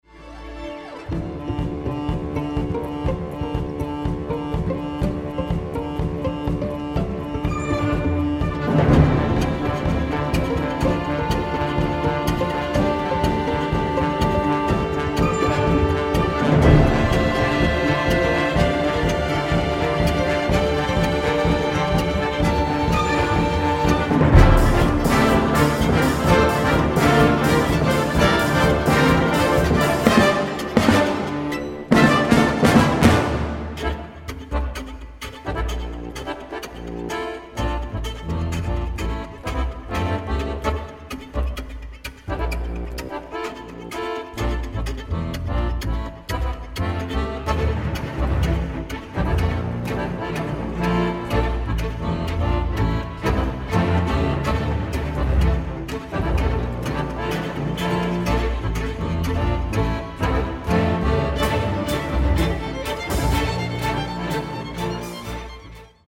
classical crossover